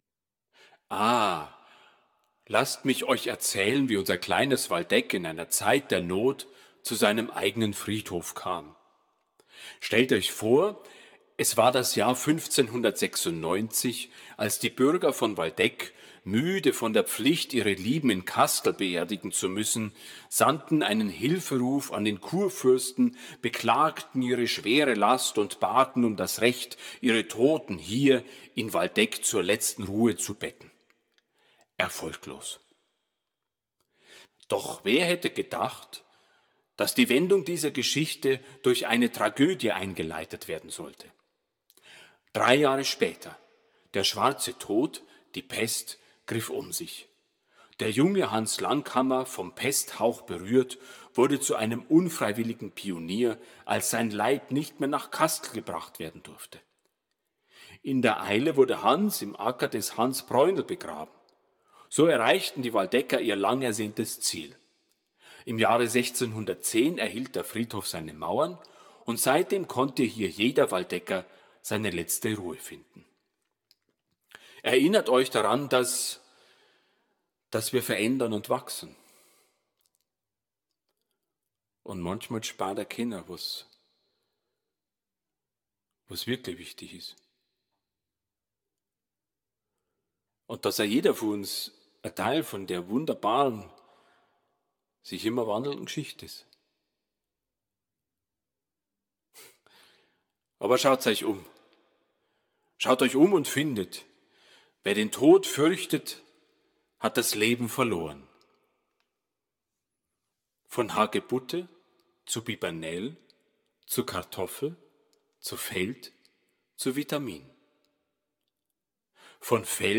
Mit einem leisen Lachen, das fast wie das Rauschen des Windes klingt, verblasst der Geist.